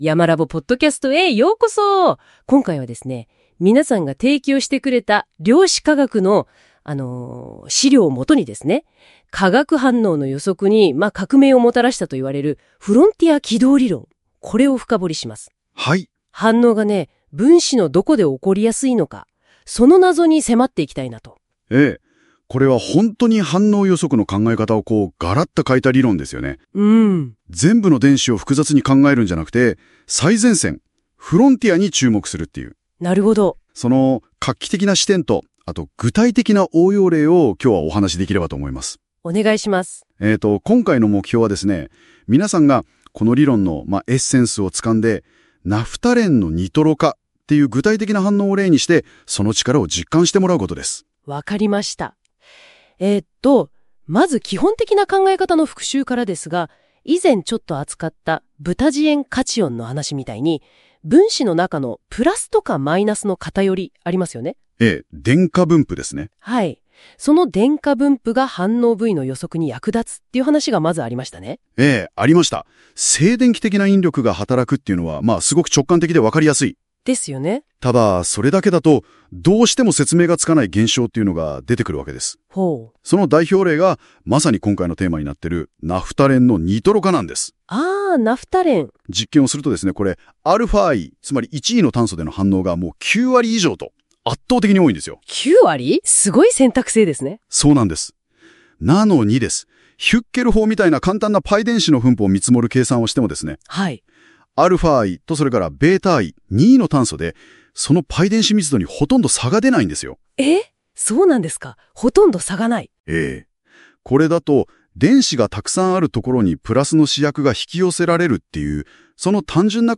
量子化学２（第５回; 2020年版）の「過去の講義動画 」をもとに、講義の主要なトピックについて「２人のＡＩホストがおしゃべりする」というポッドキャスト風の音声概要を作りました。
Audio Channels: 1 (mono)